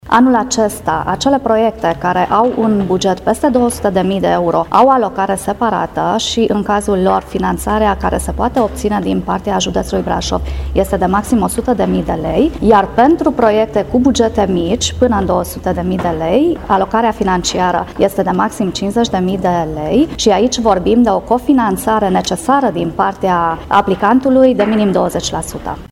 Pentru proiectele culturale din 2018 este prevăzută suma de un milion de lei, însă există două axe de finanțare, cu particularități delimitate clar, despre care ne-a oferit detalii Imelda Toaso, vicepreședinte al CJ Brașov: